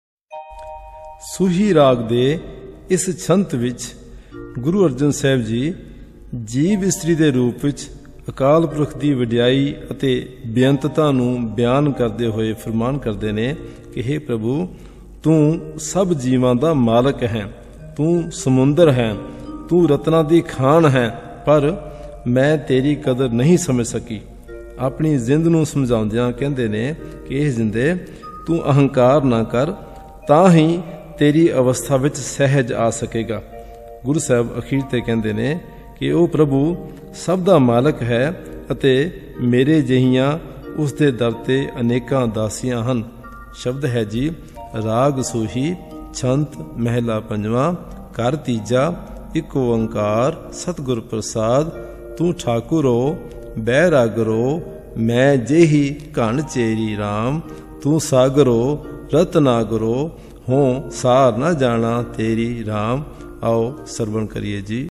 Sung in Raag Suhi; Taals Rupak(7), Deepchandi(14).
This entry was posted in Shabad Kirtan and tagged , , , .